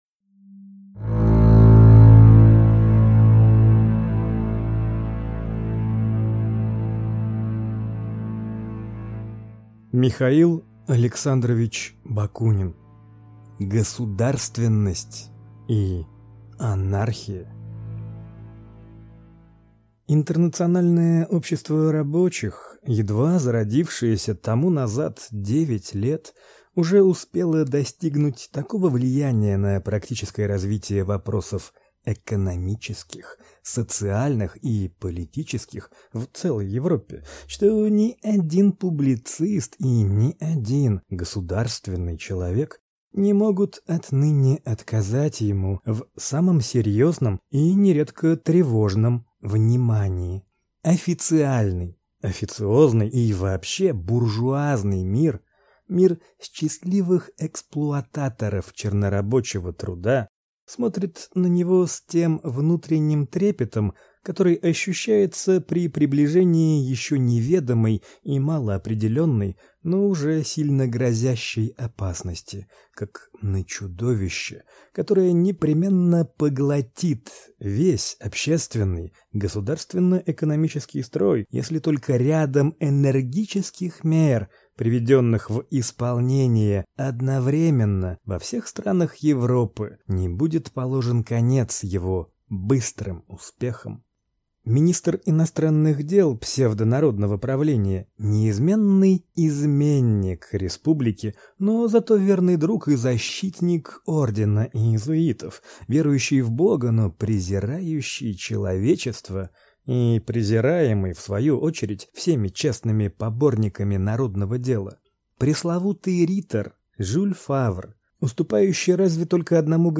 Аудиокнига Государственность и Анархия | Библиотека аудиокниг